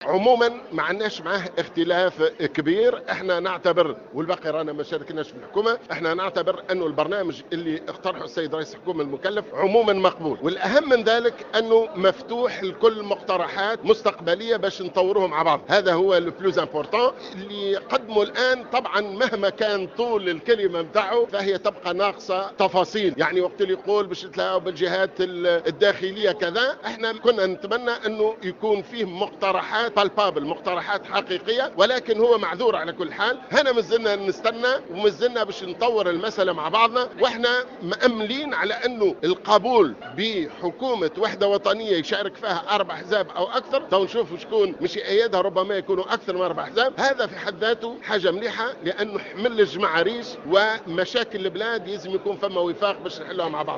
Le député du mouvement Ennahdha à l’ARP Mohamed Ben Salem, a considéré ce mercredi 4 février 2015 dans une intervention sur les ondes de Jawhara FM, que le programme du gouvernement Essid est acceptable en globalité.